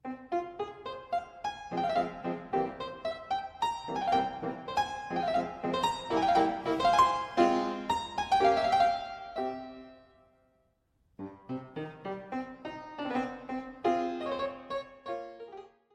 Uitgevoerd door Alexei Lubinov, op een historisch instrument.
Beethoven-Klaviesonate_Fmin_2-1-Alexei-Lubinov_Anf.mp3